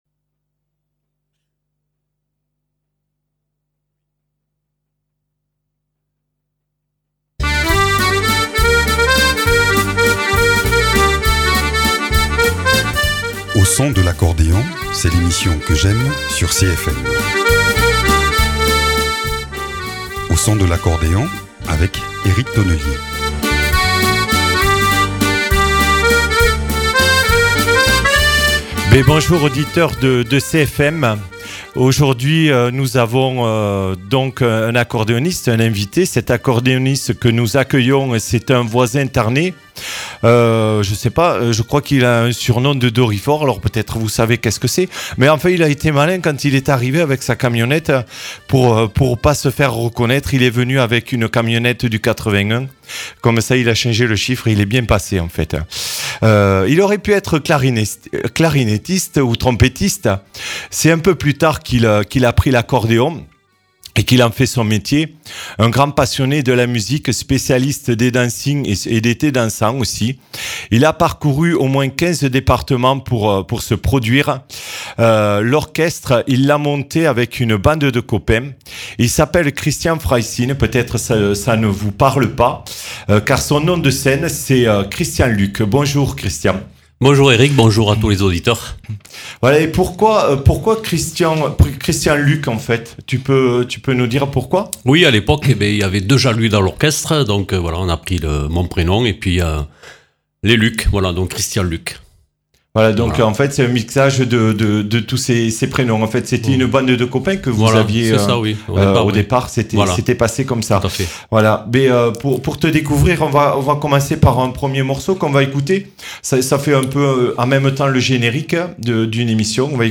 musicien-chanteur.